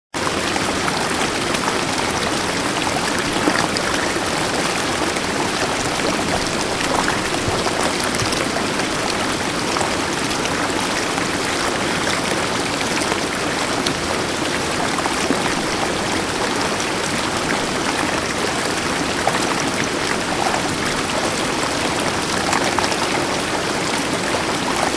Voda_WMA.wma